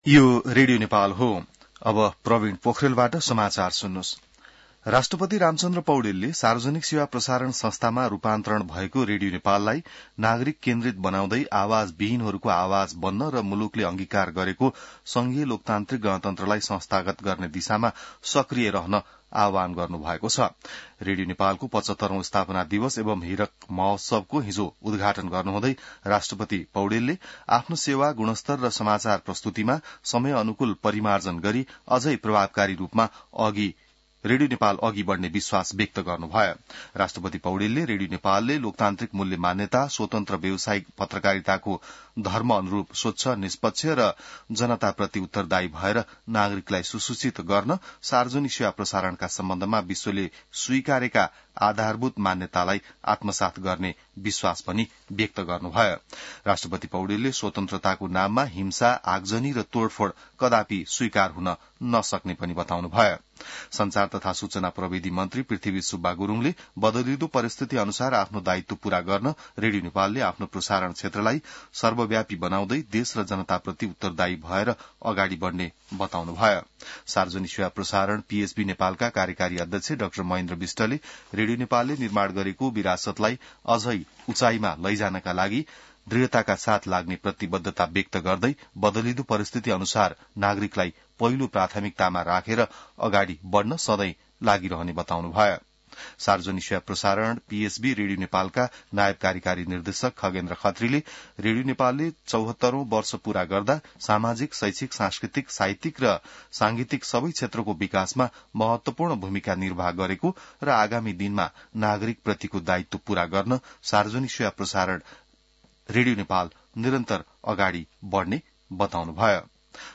बिहान ६ बजेको नेपाली समाचार : २१ चैत , २०८१